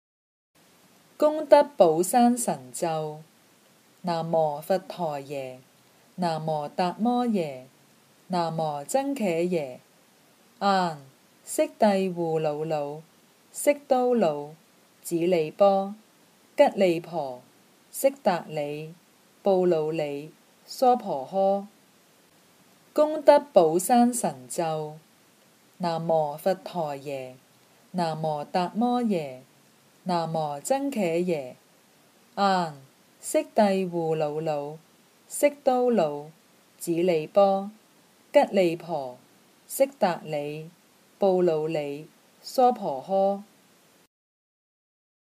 《功德宝山神咒》经文教念粤语版